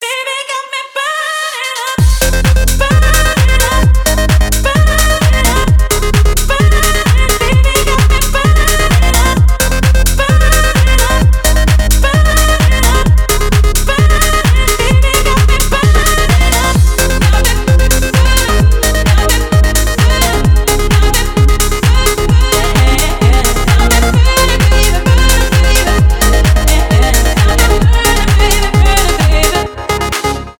энергичные , клубные
house